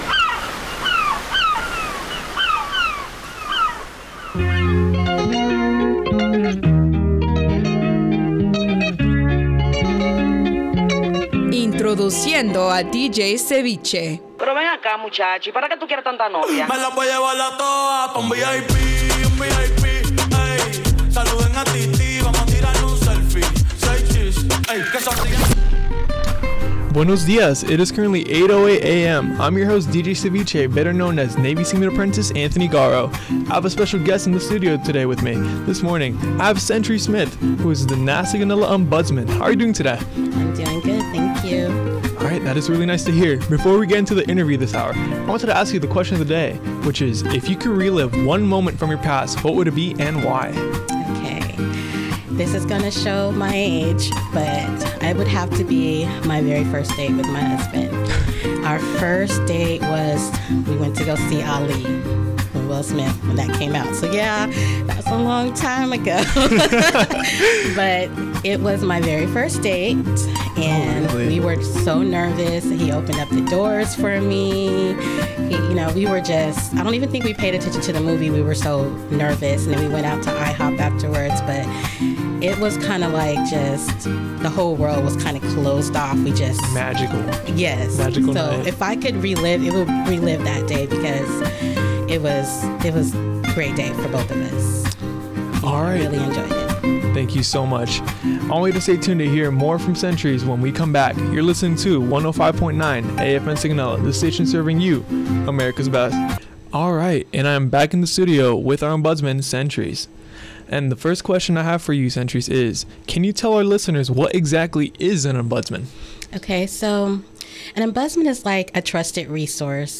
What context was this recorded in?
NAVAL AIR STATION SIGONELLA, Italy (Oct. 17, 2024) An interview